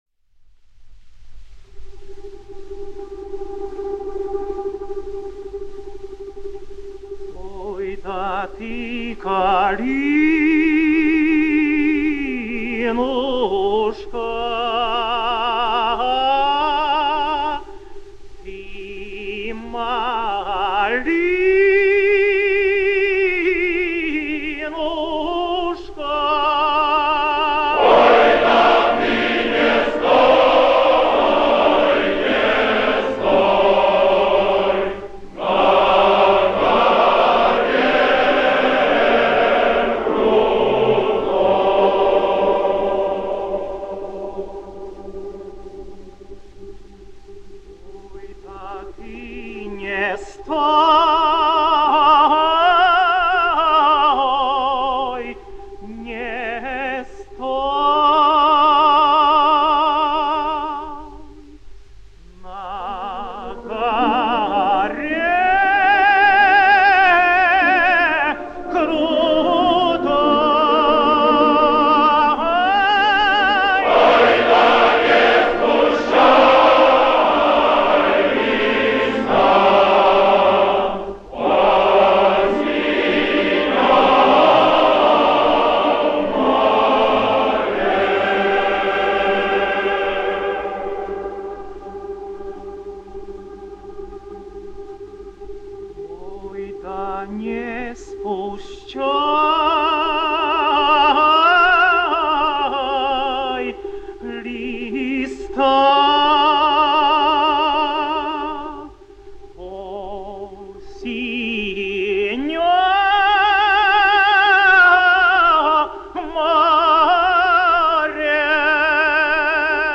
Here is also a choral version